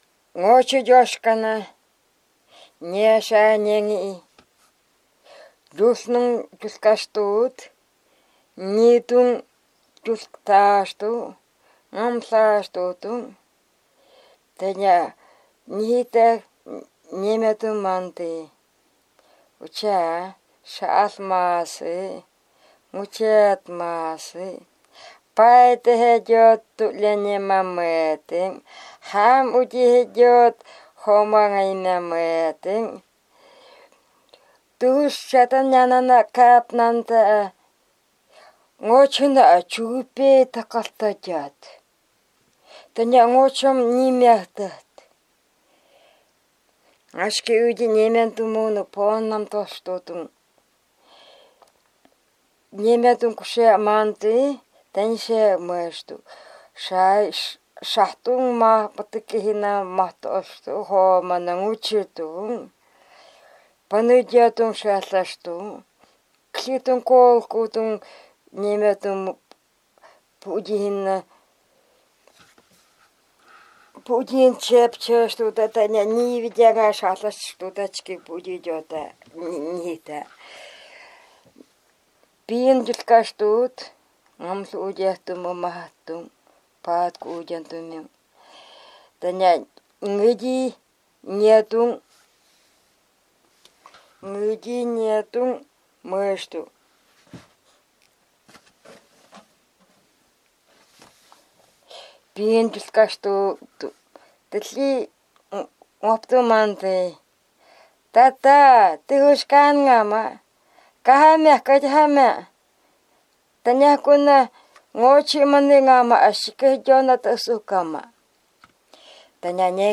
Место записи: д. Харампур